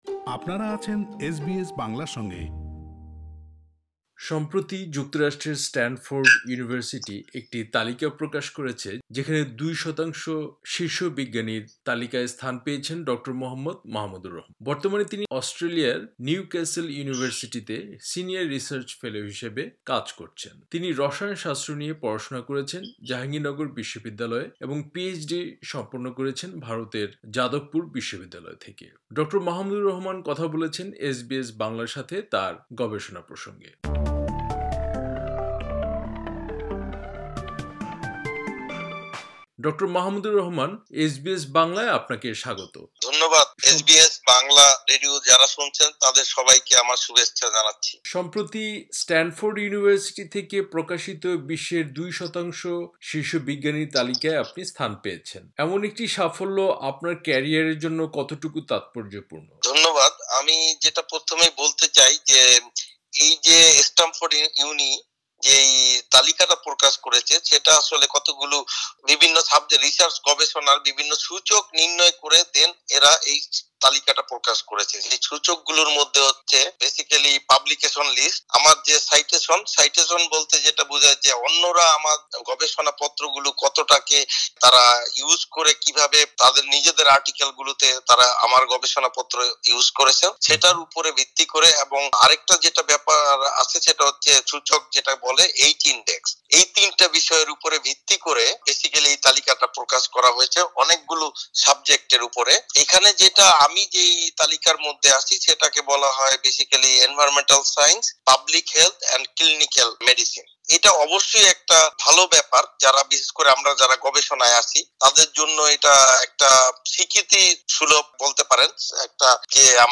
পুরো সাক্ষাৎকারটি